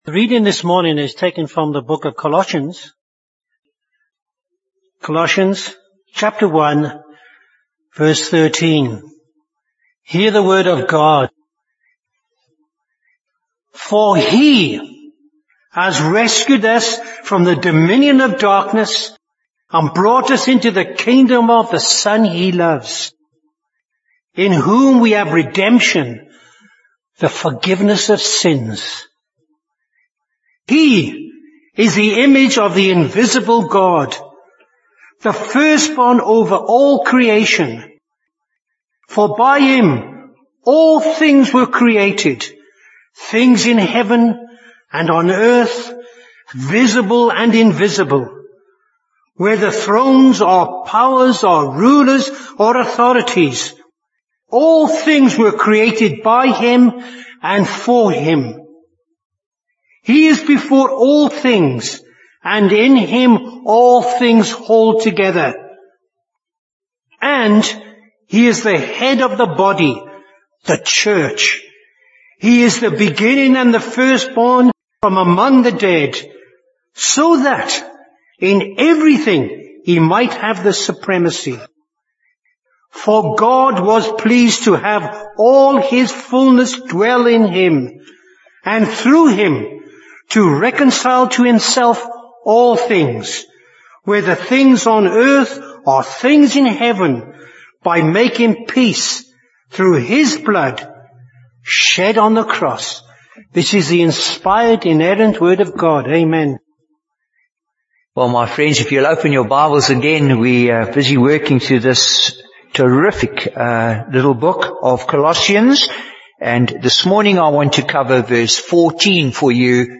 Bible Text: Colossians 1: 13-20 | Preacher: Bishop Warwick Cole-Edwards | Series: Colossians